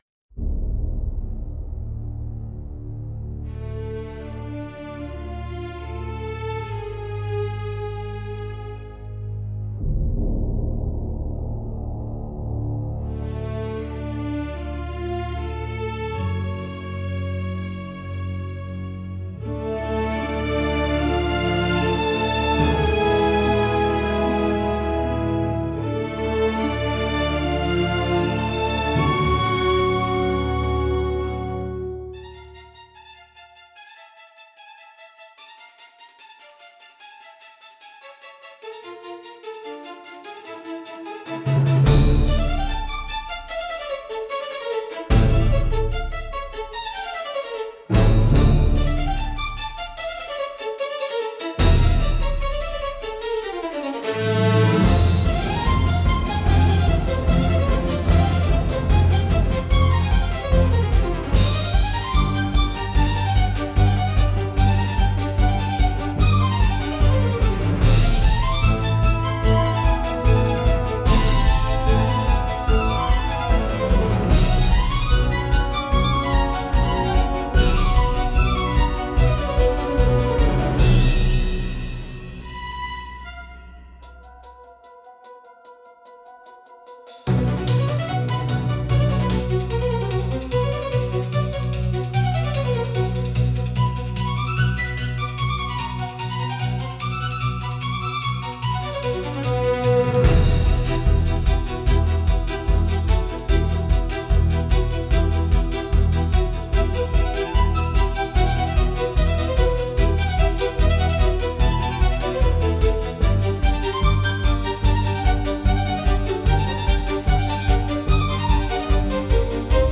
Instrumental / 歌なし
🌍 Fast, bold, and dramatic.
スピード感あふれるリズムと迫力のオーケストラサウンドが駆け抜ける、 緊張感とカッコよさを兼ね備えたダイナミックな一曲！
テンポの速い展開が演技にキレと勢いをプラスしてくれます✨
スリリングでクールな雰囲気を出したい演技にピッタリのサウンドです🔥